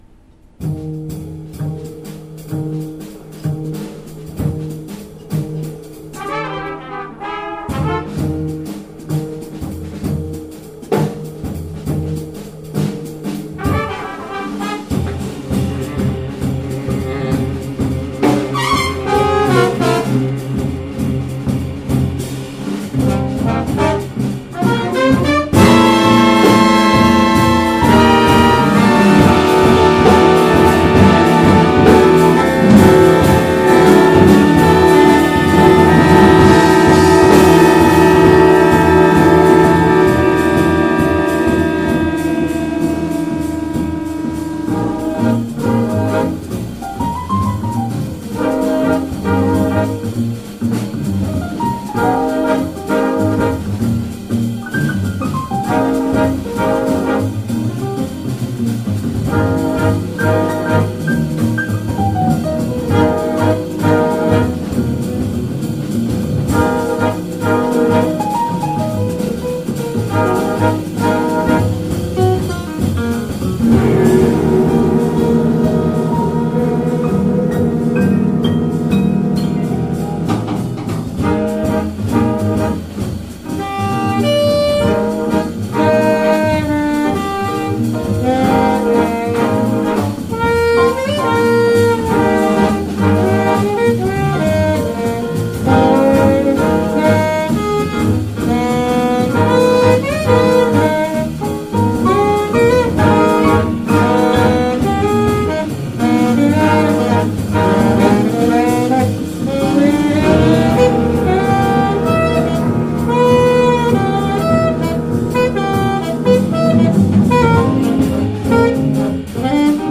j'ai précédemment parlé de ce Big band
avec cor, tuba, guitare et flûte
Avec une instrumentation pour l'essentiel acoustique".